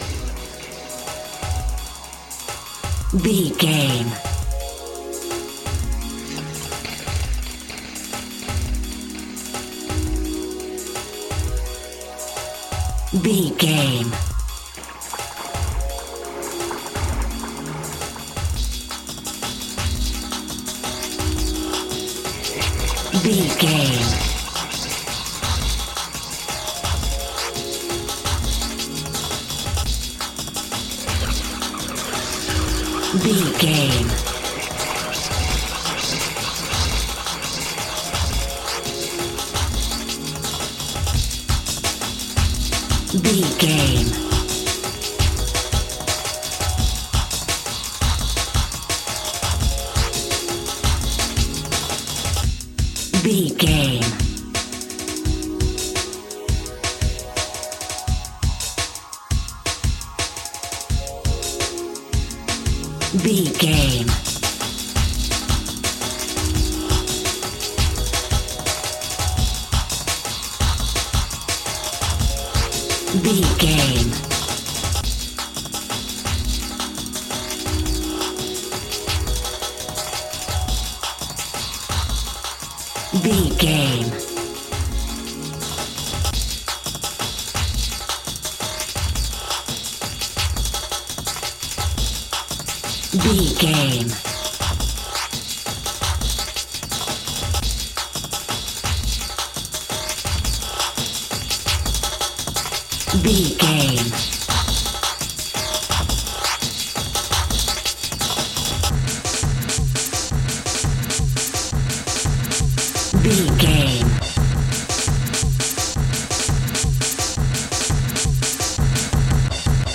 Aeolian/Minor
Fast
drum machine
synthesiser